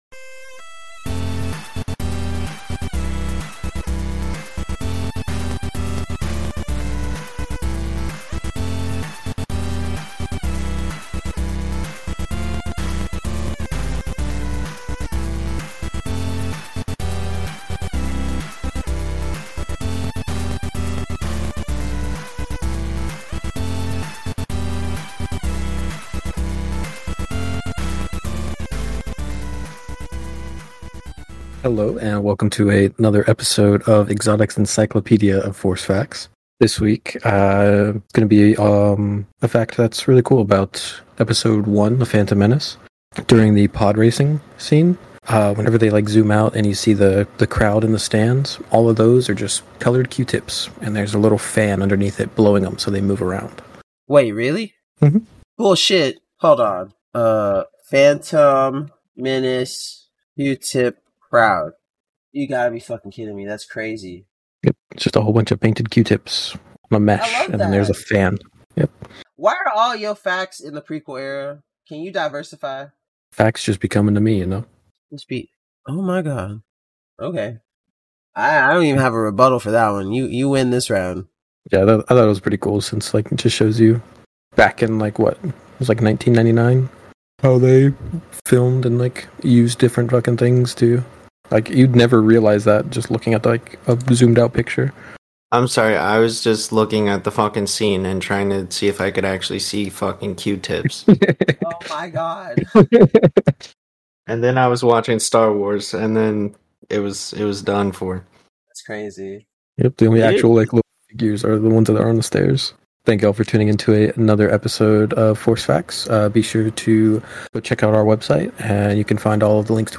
Four friends explore, discover and unravel the mysteries of the Star Wars universe, diving into both the Canon and the Legends timeline to give you all the Star Wars content you never knew you needed.